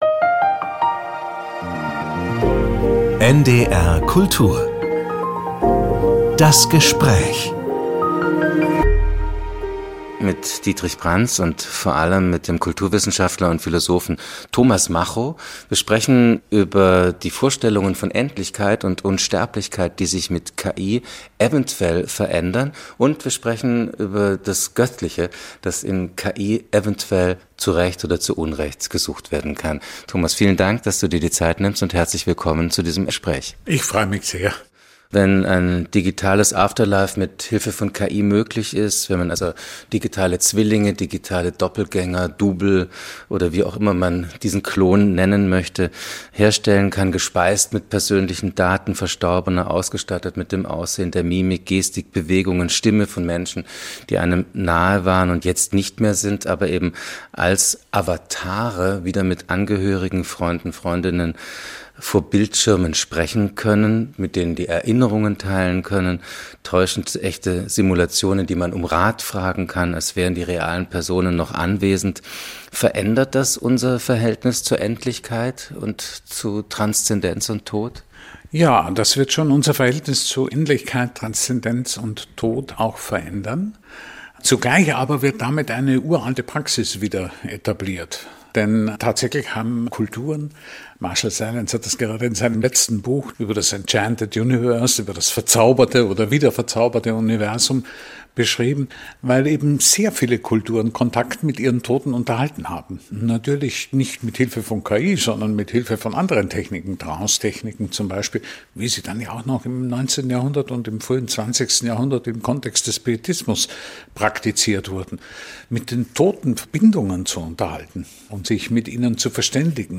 Die KI und der Tod - ein Gespräch mit Thomas Macho ~ NDR Kultur - Das Gespräch Podcast